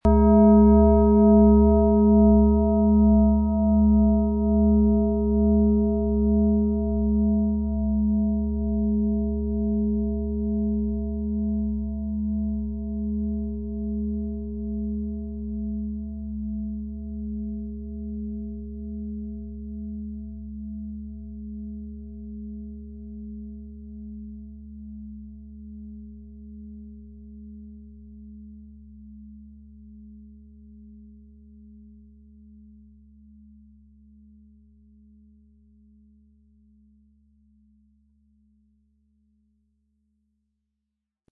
Planetenton 1
Die tiefen und resonanten Schwingungen der Klangschale helfen, alte Muster zu durchbrechen, um Raum für neue Klarheit und Kraft zu schaffen.
• Mittlerer Ton: Mond
• Höchster Ton: Uranus